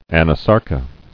[an·a·sar·ca]